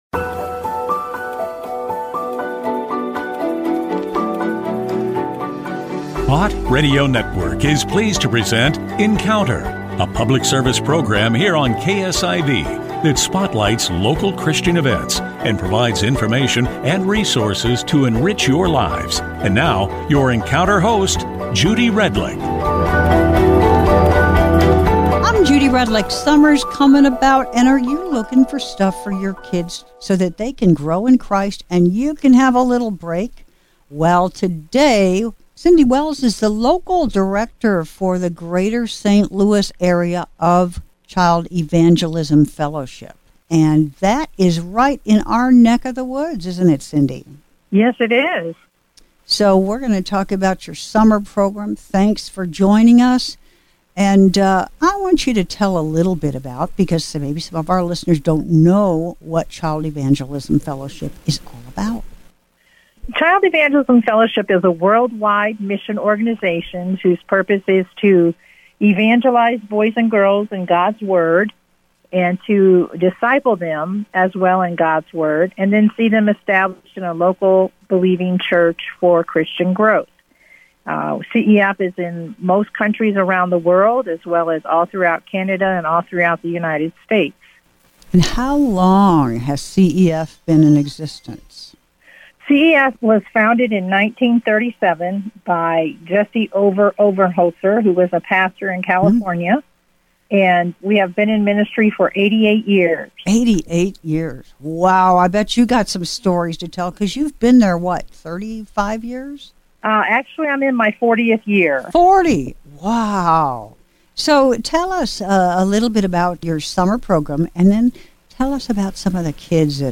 Encounter Show airs every Tuesday on KSIV radio, Bott Radio Network – St. Louis.